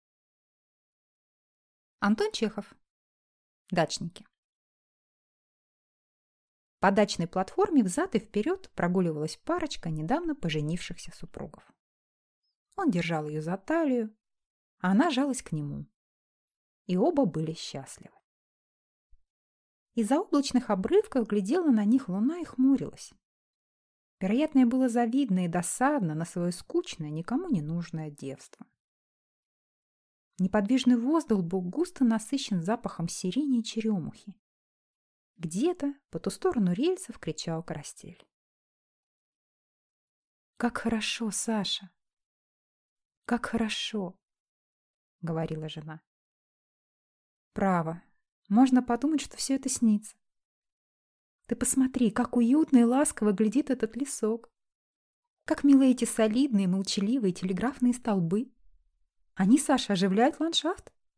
Аудиокнига Дачники | Библиотека аудиокниг